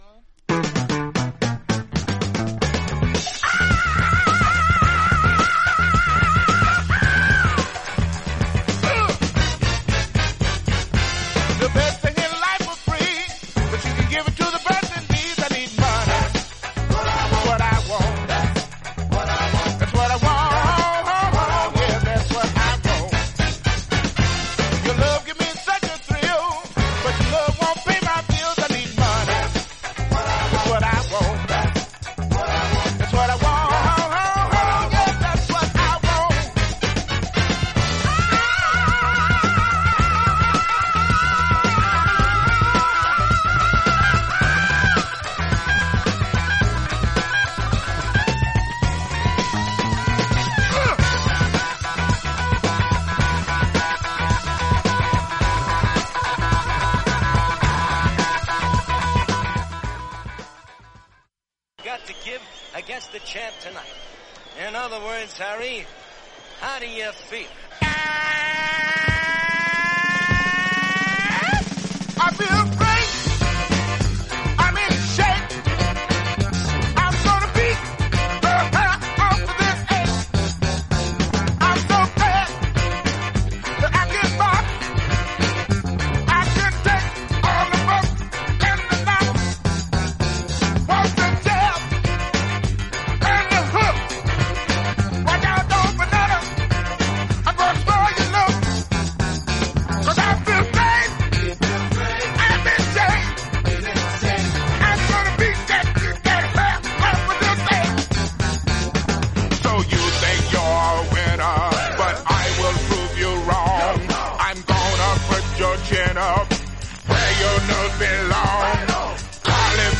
盤面は薄いスリキズがありますが音に影響ありません。
実際のレコードからのサンプル↓ 試聴はこちら： サンプル≪mp3≫